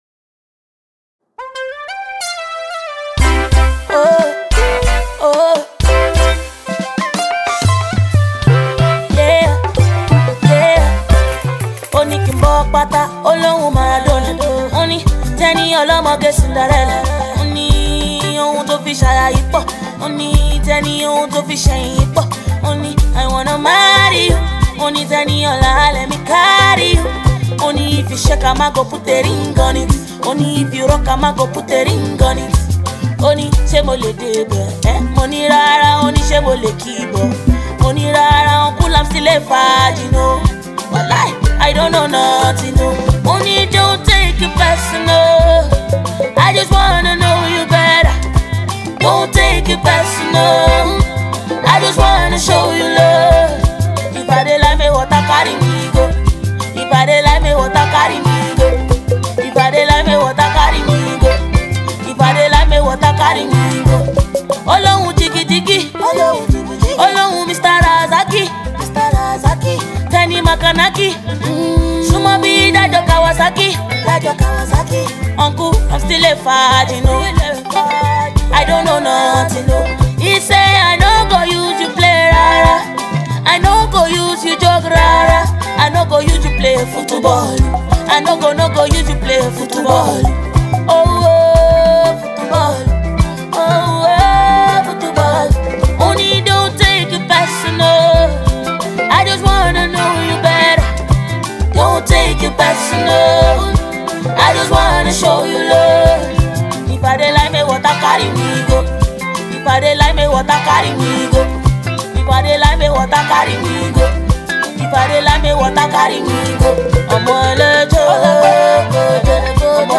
highlife beat